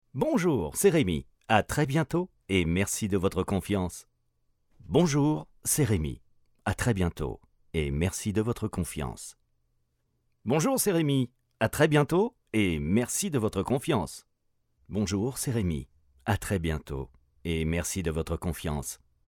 Catalogue de voix
Voix Homme